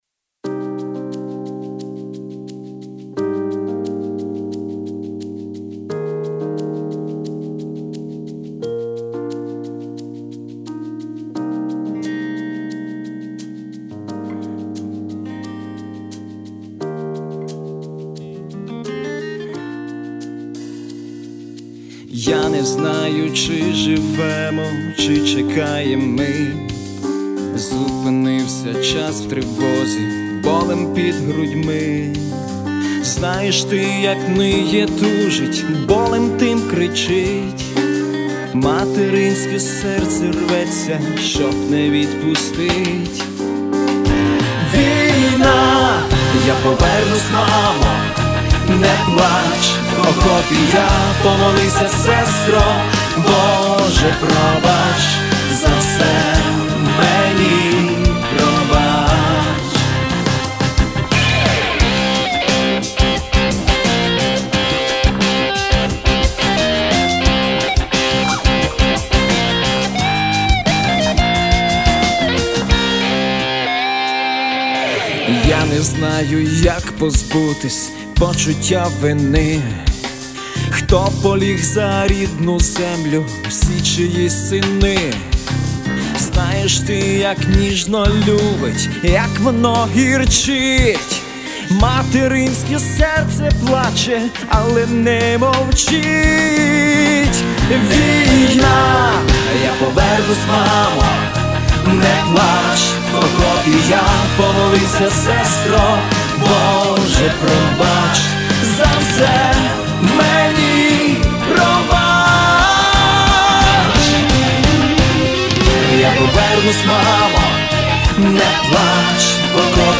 Рубрика: Рок